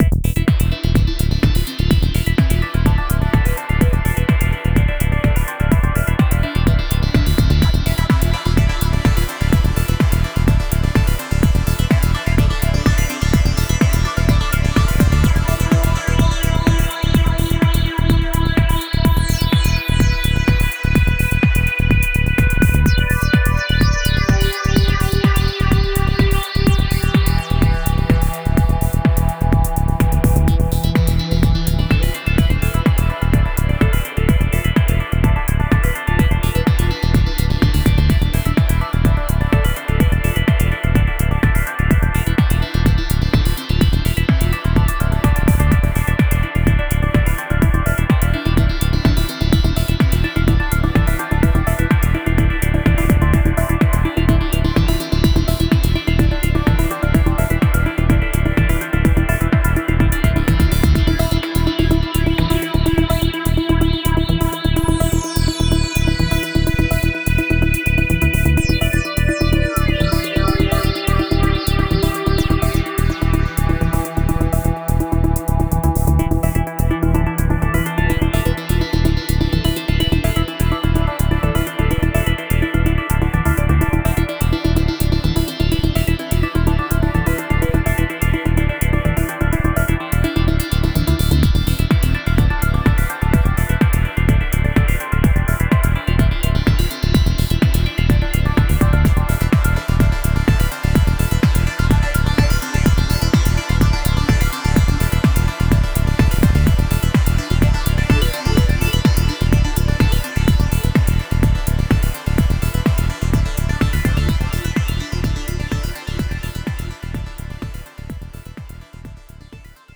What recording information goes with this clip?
Written in Buzz, remixed and updated in Ableton for the